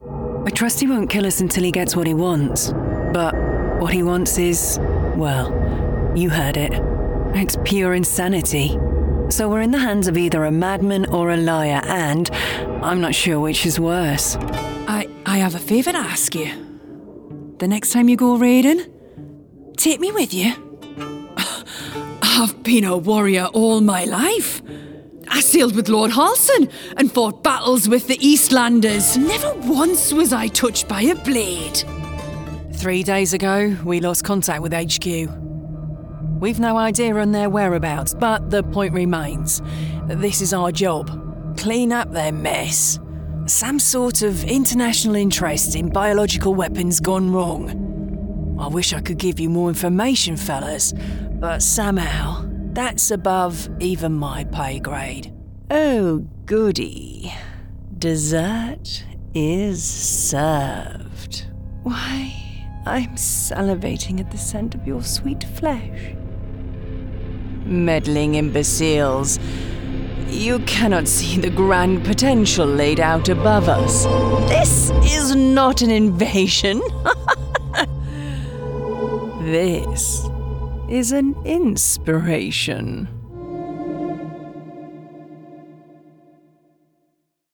Female
Bright, Confident, Corporate, Engaging, Friendly, Natural, Reassuring, Warm, Witty, Versatile
Northern (native), Geordie (native), Neutral British (native) RP, Scottish, Liverpudlian, Southern.
Microphone: Neumann TLM103, Sennheiser 416,